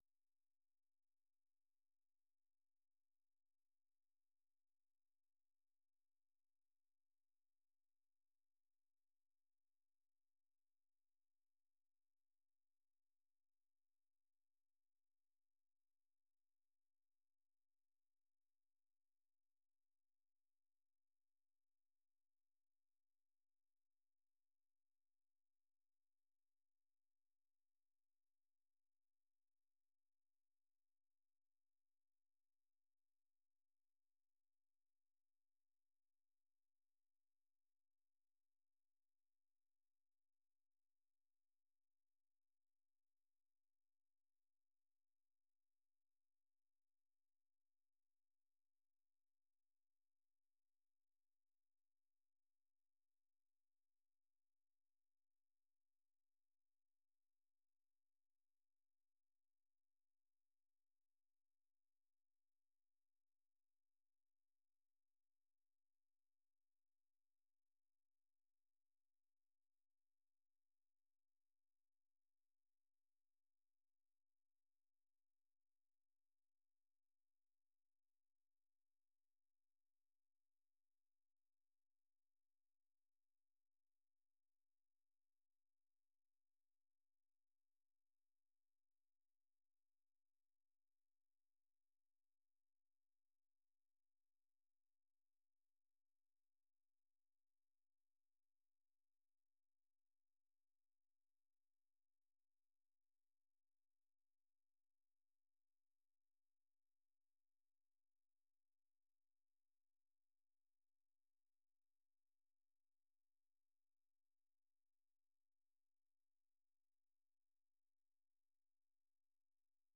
VOA 한국어 간판 뉴스 프로그램 '뉴스 투데이', 3부 방송입니다.